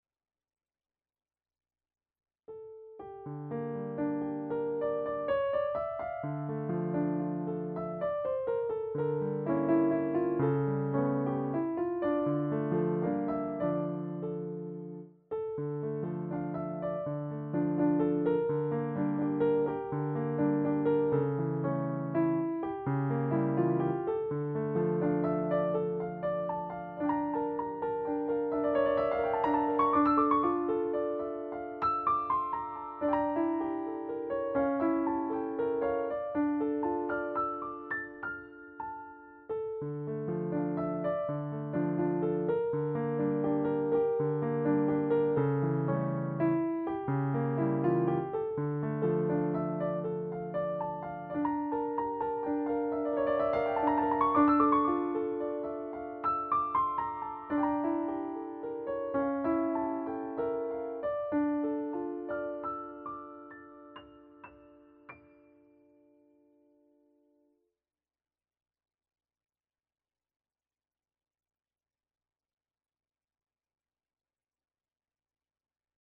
Piano s/ Clique